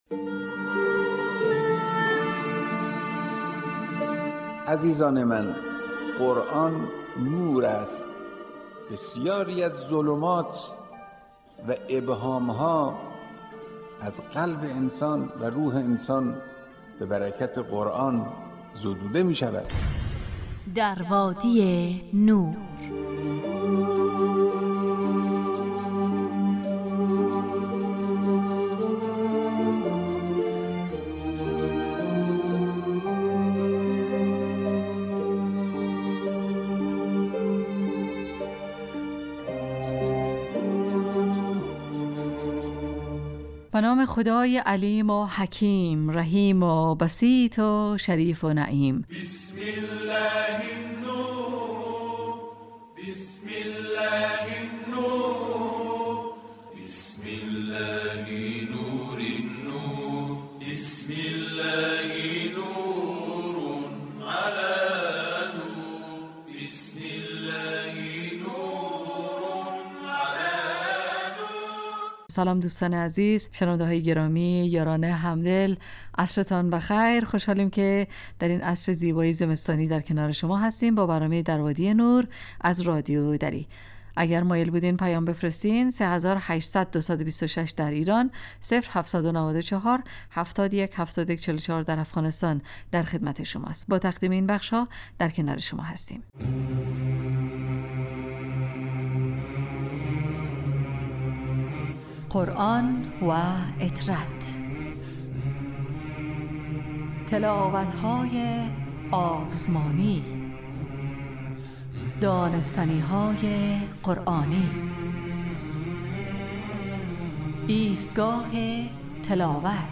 در وادی نور برنامه ای 45 دقیقه ای با موضوعات قرآنی روزهای فرد: ( قرآن و عترت،طلایه داران تلاوت، ایستگاه تلاوت، دانستنیهای قرآنی، تفسیر روان و آموزه های زند...